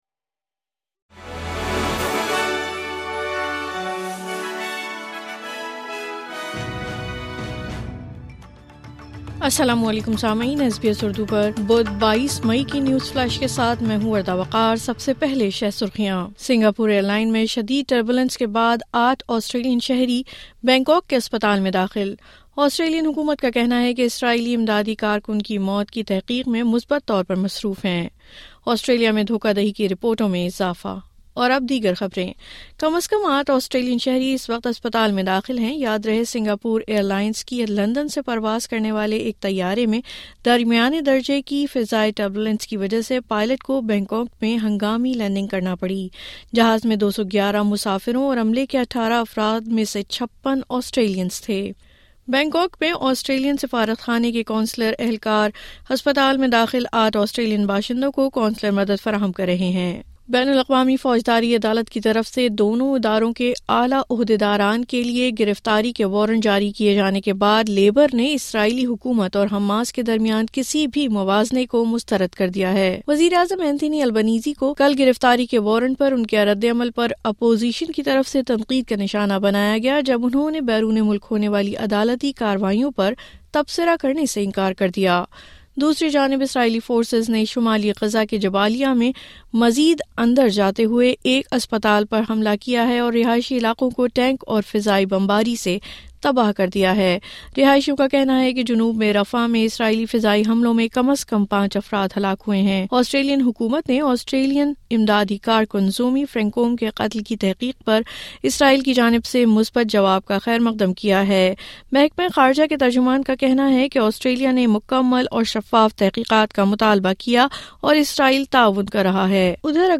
نیوز فلیش:22 مئی 2024: سنگاپور ائیرلائن کی پرواز میں ٹربیولنس۔آٹھ آسٹریلینز اسپتال میں داخل